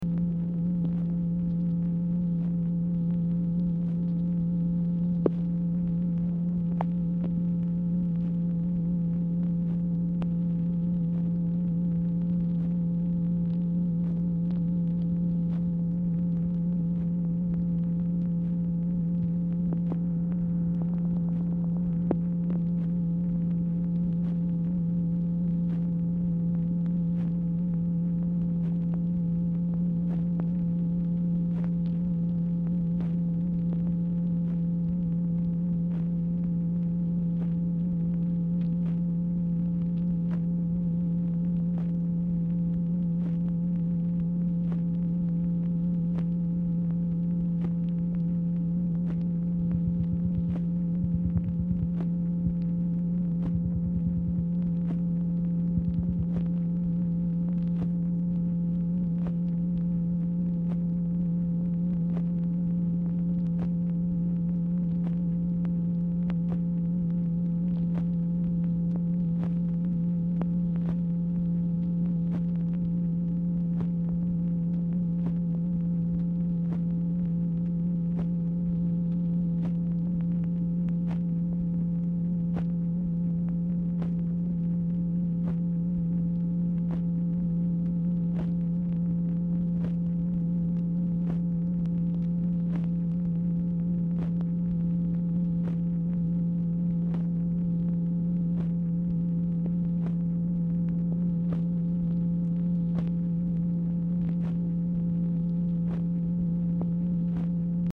Telephone conversation # 9804, sound recording, MACHINE NOISE, 3/1/1966, time unknown | Discover LBJ
Format Dictation belt